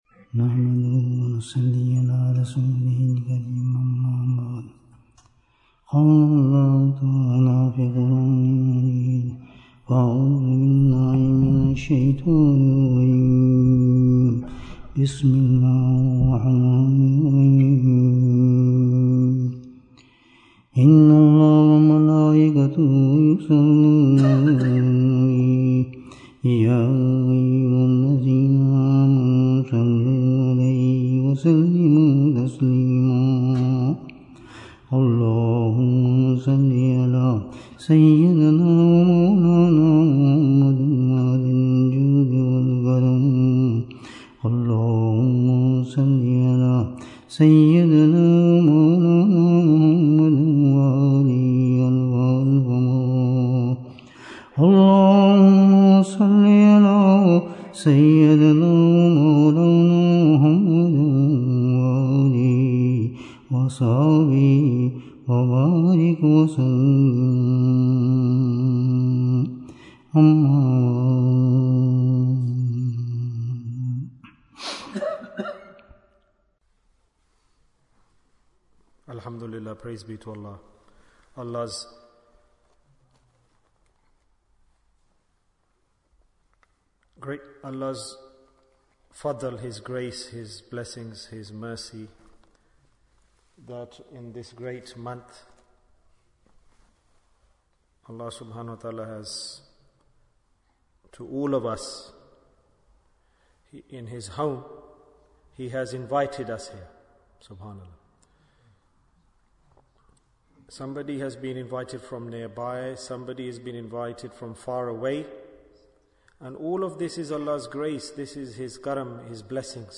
Majlis-e-Dhikr in Manchester Bayan, 42 minutes8th June, 2024